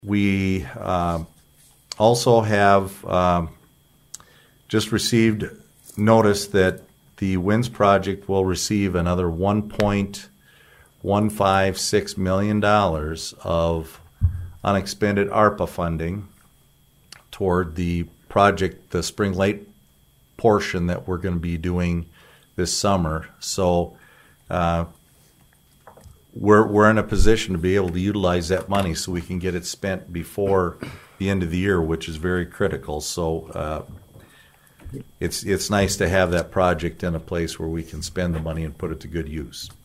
ABERDEEN, S.D.(HubCityRadio)- The Aberdeen City Manager, Robin Bobzien updated on the water projects during Monday’s City Council meeting.